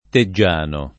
Teggiano [ te JJ# no ]